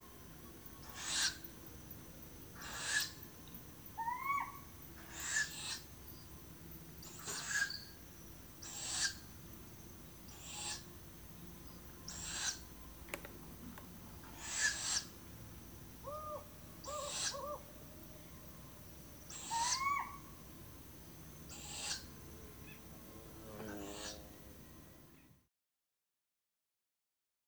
4-54-Humes-Owl-Begging-Calls-Of-Nestlings-With-Soliciting-Calls-Of-Female-Distant-Compound-Hooting-Of-Male.wav